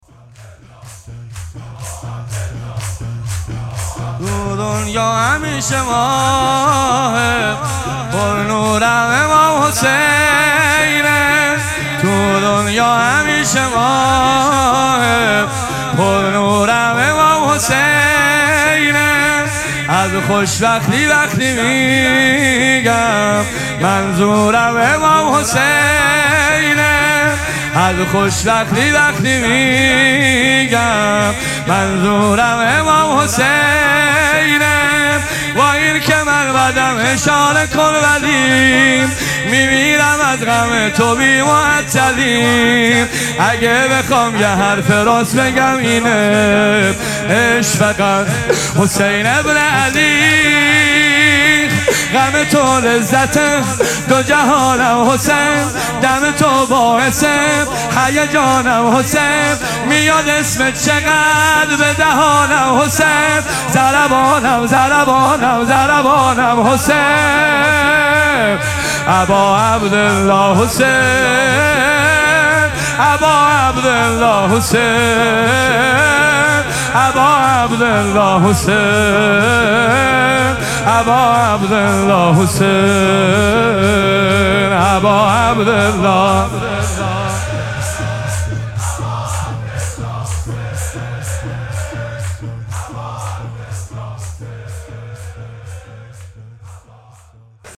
مراسم مناجات شب چهاردهم ماه مبارک رمضان
شور
مداح